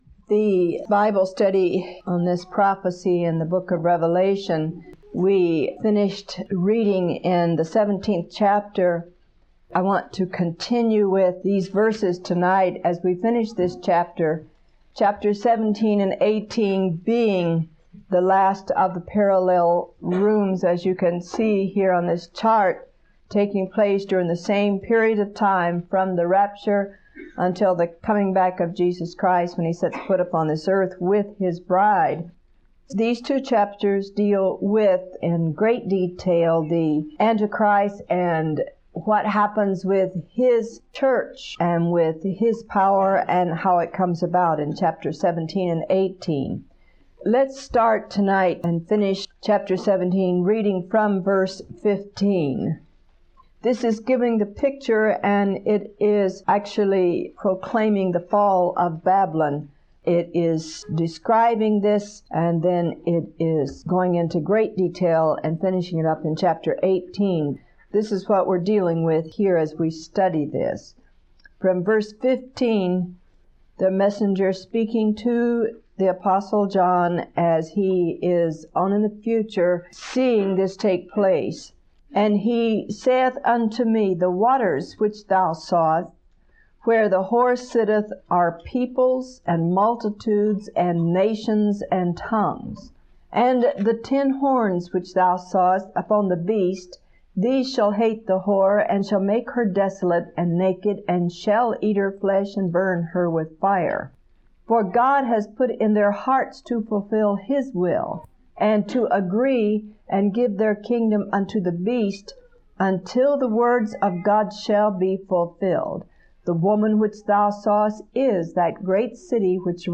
January 28, 1987 – Teaching 55 of 73